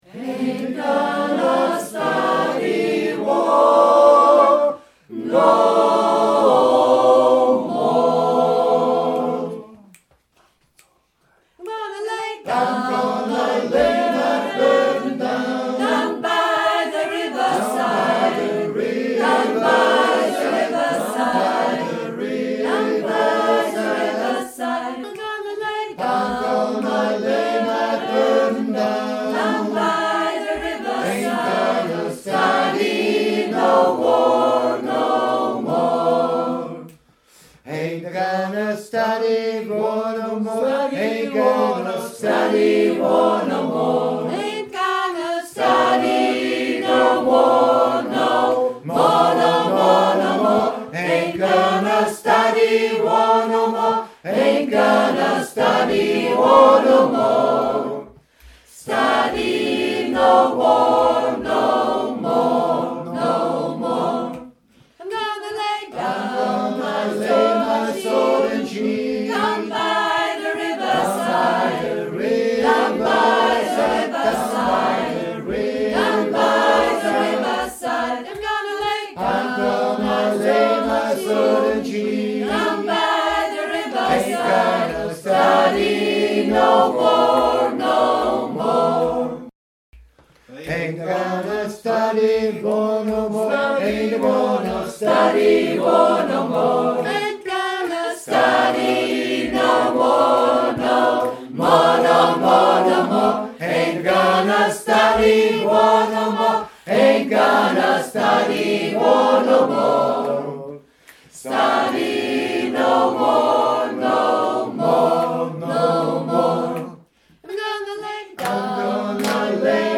XANGSMEIEREI-Probe 03.10.2016